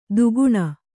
♪ duguṇa